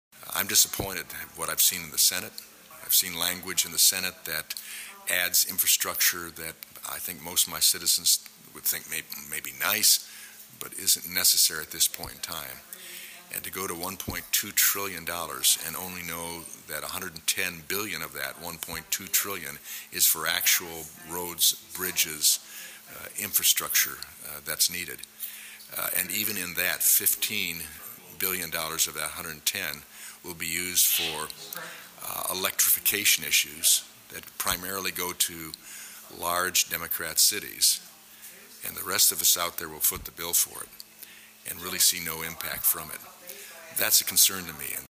He told WLEN News that he was disappointed in some of the language in the bill, adding things that he says may be nice…but are not necessary at this time…